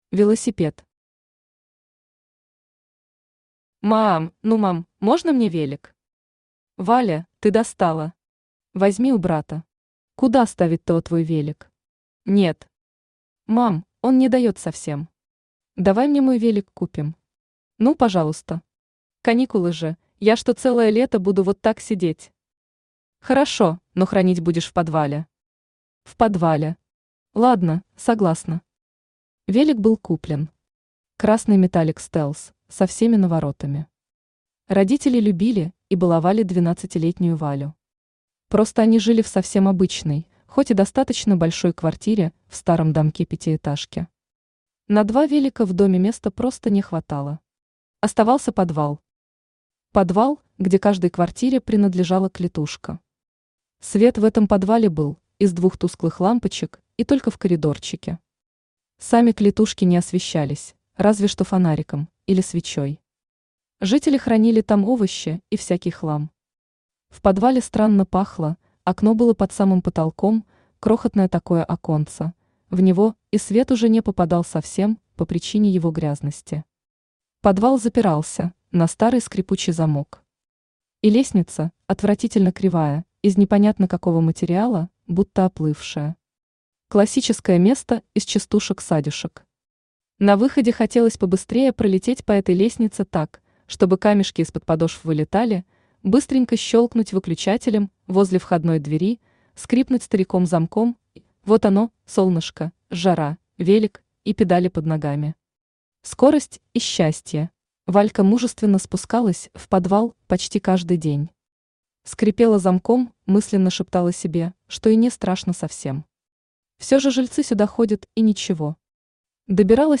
Аудиокнига Соседи | Библиотека аудиокниг
Aудиокнига Соседи Автор Лариса Анатольевна Вайсерова Читает аудиокнигу Авточтец ЛитРес.